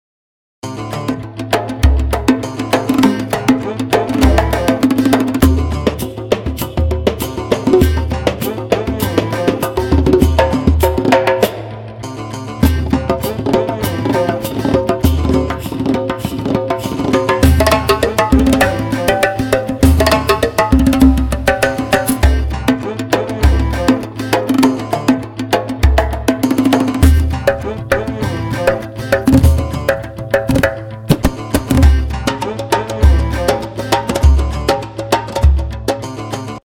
پکیج لوپ پرکاشن ۴/۴ پرشین لوپ
مجموعه‌ای تخصصی شامل ۱۵ لوپ‌ پرکاشن در ریتم ۴/۴ که با دقت بالا و اجرای زنده در استودیو ضبط شده‌اند. این پکیج با تمرکز بر ریتم‌های پرانرژی و کاربردی طراحی شده تا در سبک‌های پاپ، مارکتی، ترپ و الکترونیک به‌خوبی مورد استفاده قرار گیرد.
تمامی لوپ‌ها با کوالیتی استودیویی، تمپوهای متنوع و هماهنگی کامل با پروژه‌های دیجیتال ساخته شده‌اند تا روند تنظیم و ساخت آهنگ برای شما سریع‌تر و دقیق‌تر شود.
این محصول انتخابی ایده‌آل برای تنظیم‌کنندگان و آهنگ‌سازانی است که به دنبال صدایی تمیز، پرقدرت و استاندارد در ریتم‌های ۴/۴ هستند.
4_4-percussion-vip.mp3